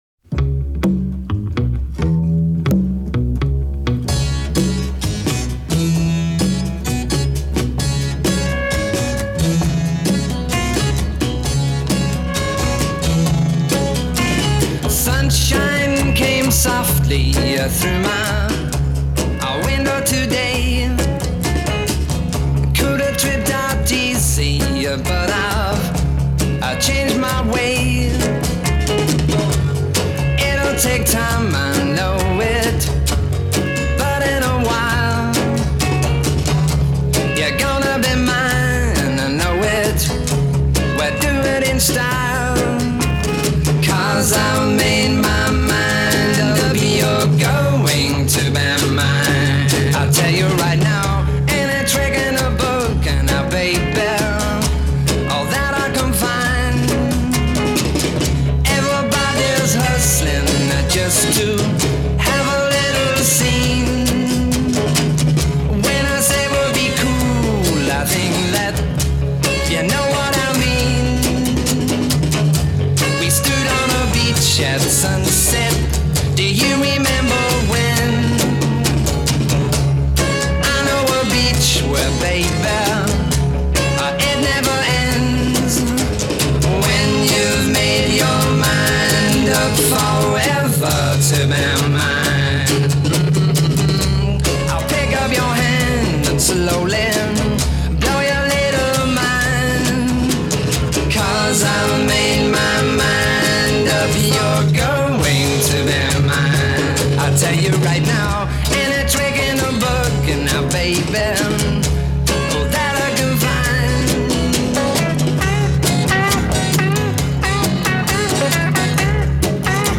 类型：Folk